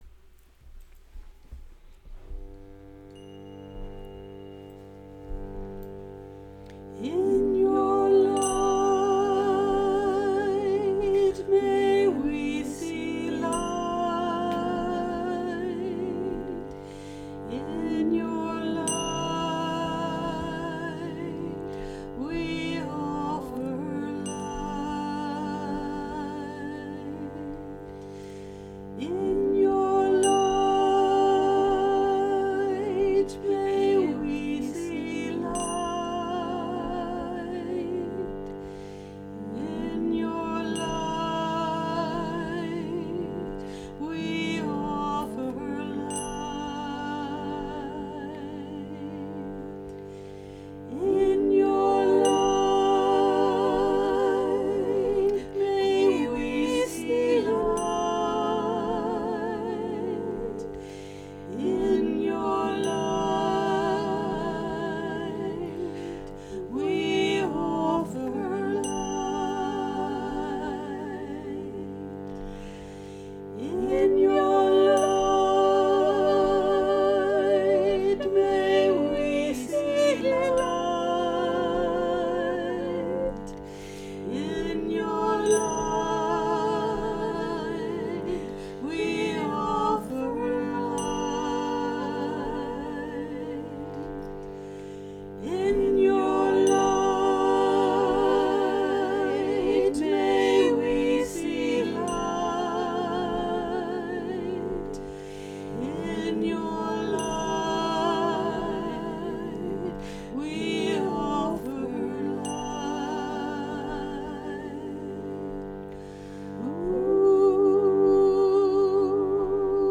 Chant: In your light, may we be light, in your light, we offer light